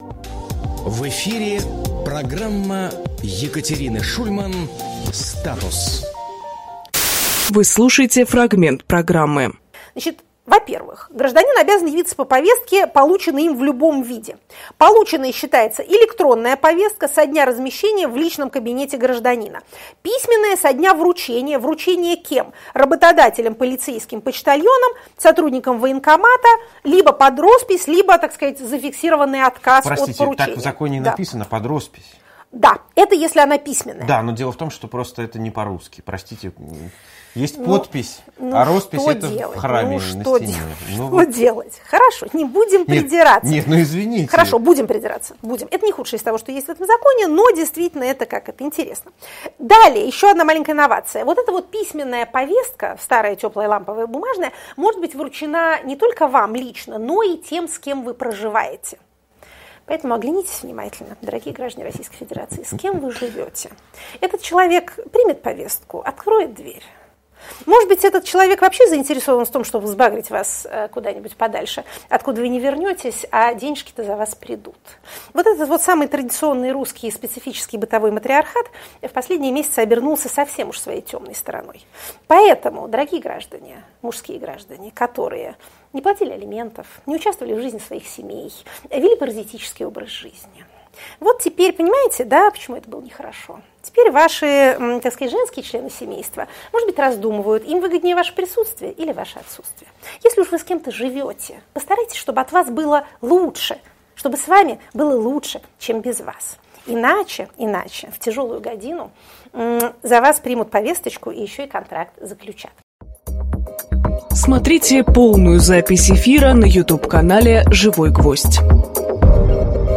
Екатерина Шульманполитолог
Фрагмент эфира от 11.04.23